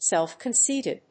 アクセントsélf‐concéited